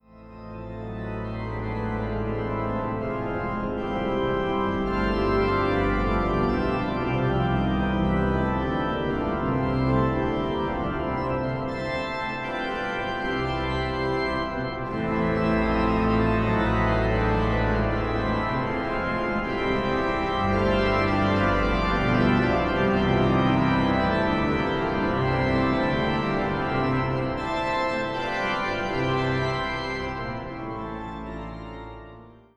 an der Orgel der Jesuitenkirche St. Michael zu München